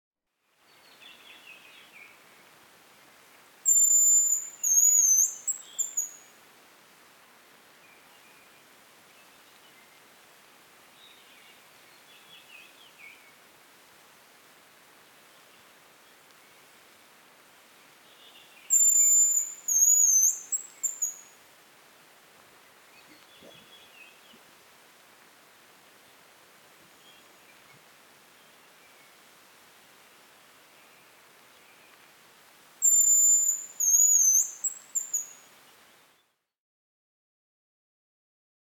Звуки манка
Рябчик обыкновенный